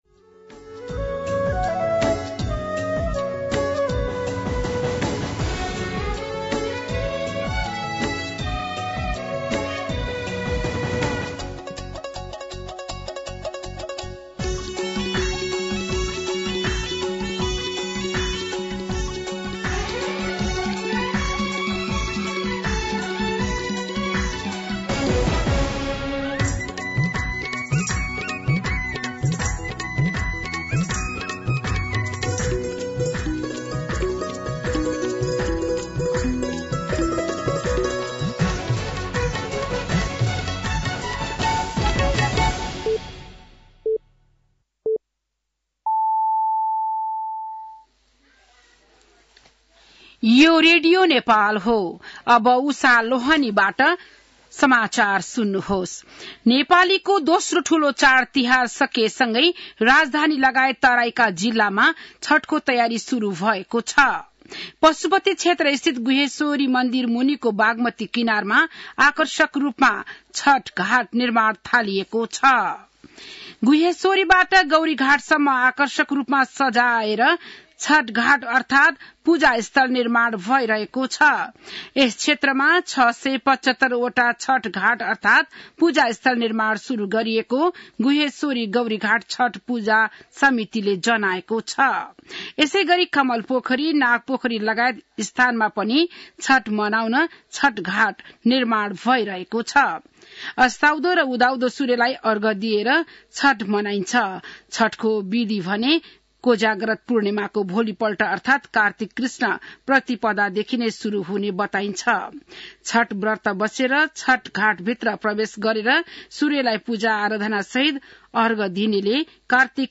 बिहान ११ बजेको नेपाली समाचार : २० कार्तिक , २०८१